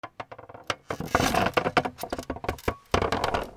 椅子のきしみ ゆっくり 『ギギギ』
/ J｜フォーリー(布ずれ・動作) / J-22 ｜椅子